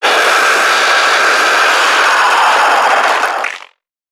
NPC_Creatures_Vocalisations_Infected [2].wav